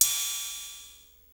Index of /90_sSampleCDs/Roland L-CD701/DRM_Drum Machine/DRM_Cheesy DR55
CYM 110 CY0A.wav